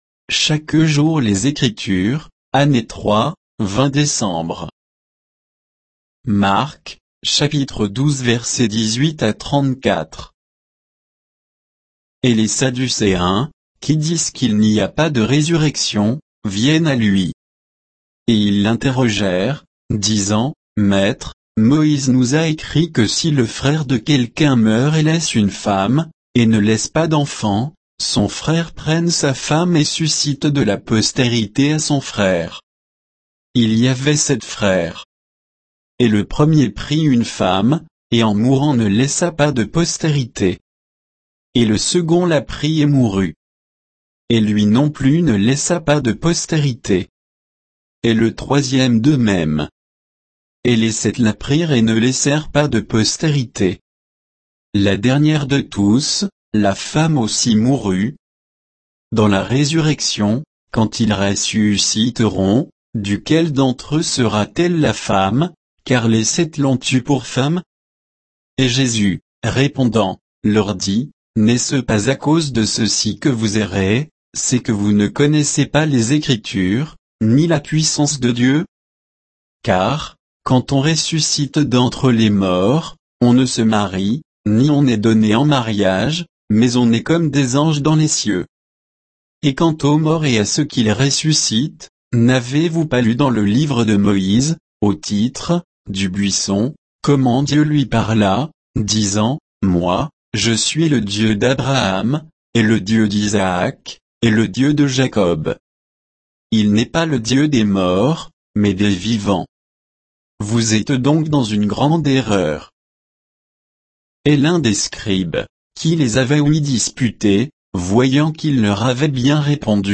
Méditation quoditienne de Chaque jour les Écritures sur Marc 12